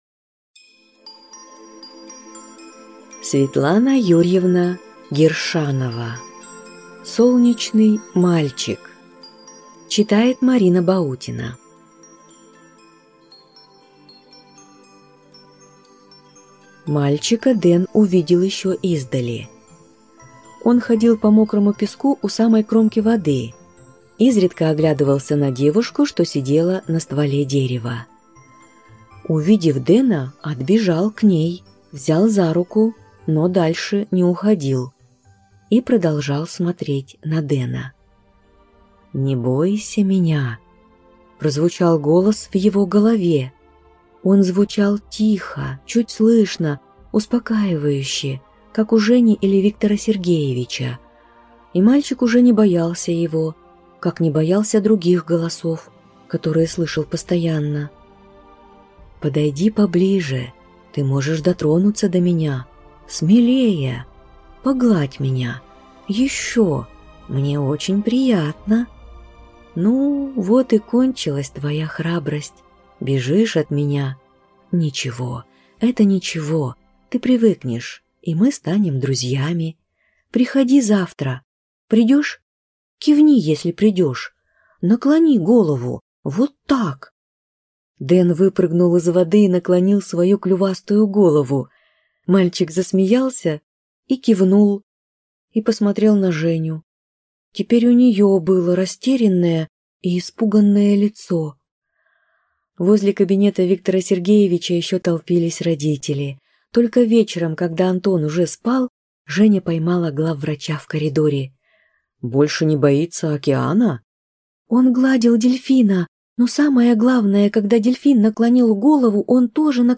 Aудиокнига Солнечный мальчик